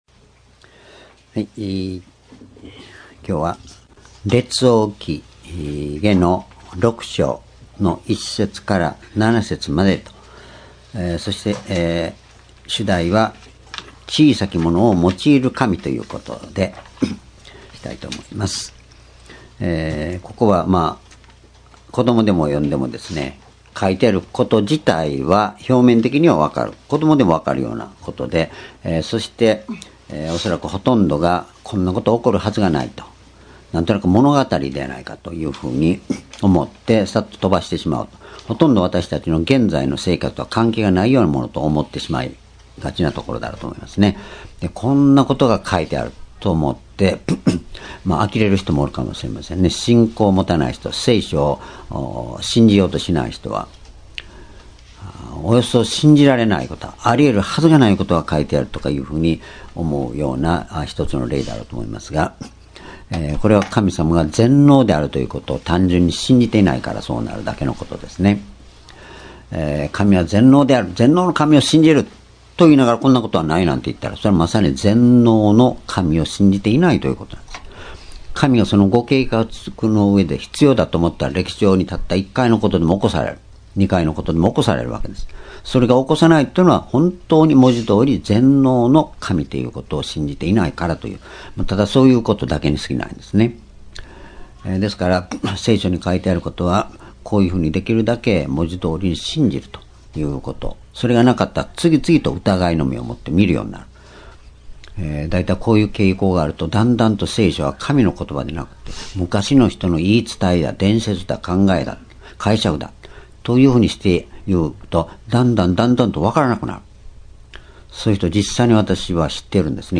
（主日・夕拝）礼拝日時 2019年2月3日 主日 聖書講話箇所 「小さきものを用いる神」 列王記下6章1節～7節 ※視聴できない場合は をクリックしてください。